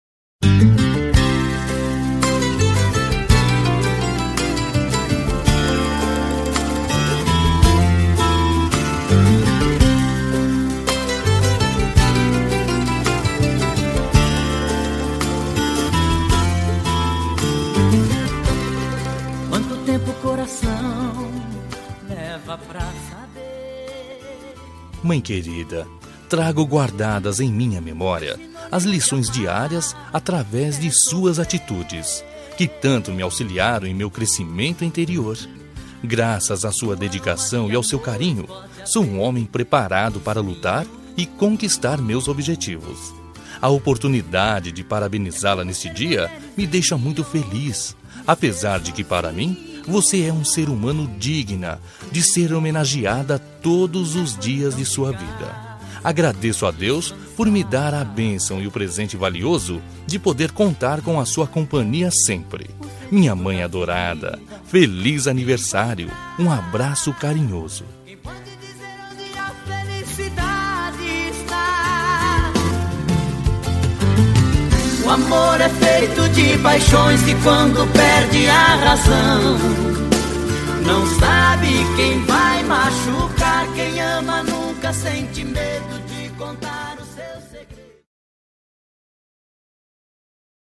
Aniversário de Mãe – Voz Masculina – Cód: 035391